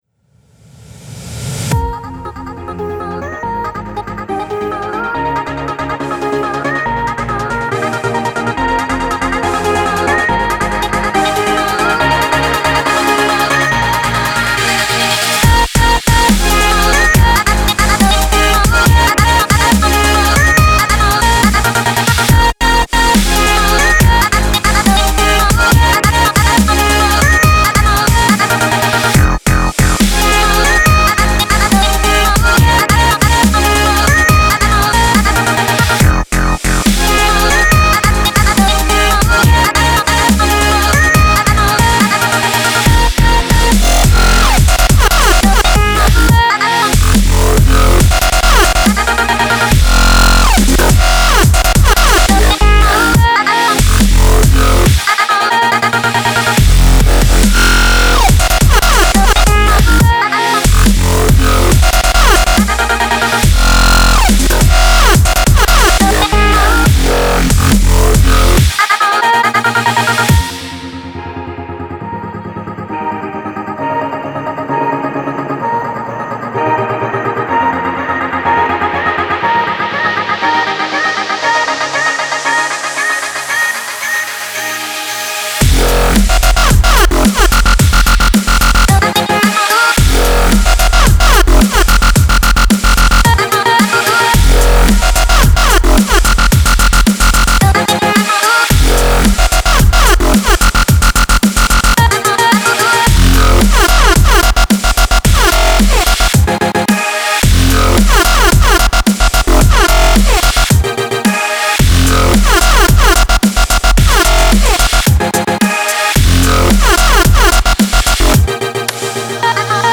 BPM110-140
Audio QualityMusic Cut